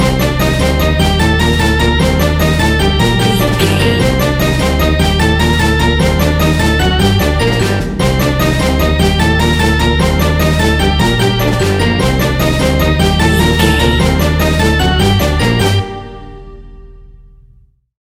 In-crescendo
Thriller
Aeolian/Minor
Fast
ominous
dark
dramatic
eerie
energetic
brass
synthesiser
drums
horror music